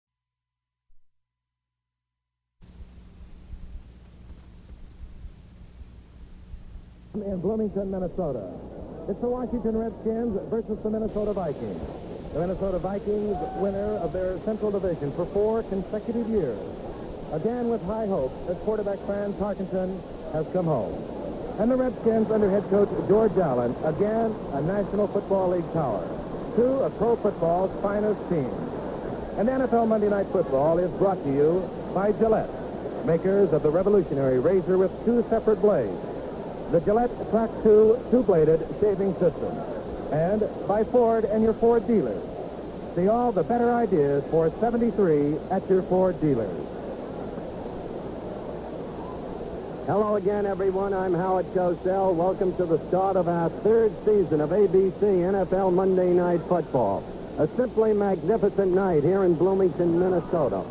Removing hiss from an old TV recording
I have an recording of an old TV program that was recorded by placing a cassette player in front of the TV. Unfortunately, there is a constant hiss.
That segment in particular has a significant noise change about a third of the way through.
There’s nothing but noise above 4000 Hz, so “Lowpass: 4000Hz 48 dB per octave” will remove a lot of the high end hiss.
There’s also a peak at 60 Hz, so run it twice, once at 60 Hz and once at 180 Hz.